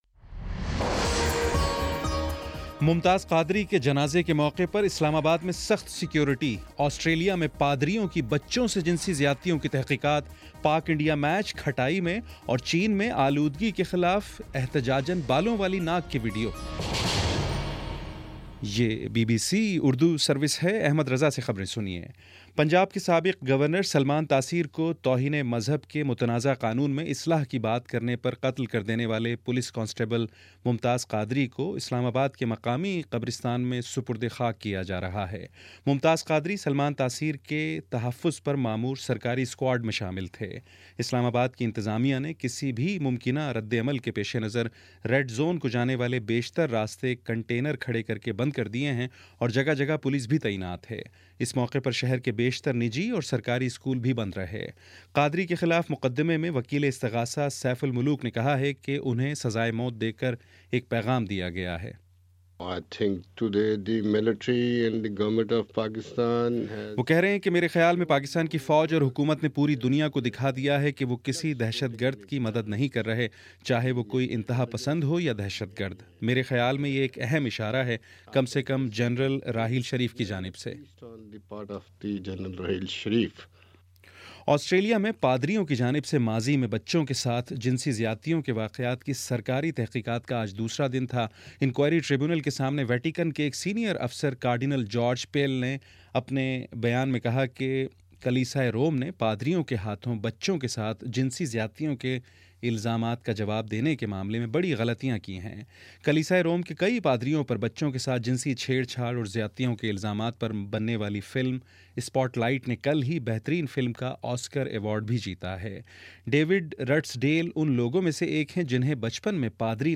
مارچ 01 : شام پانچ بجے کا نیوز بُلیٹن